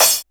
Tr8 Cymbal 01.wav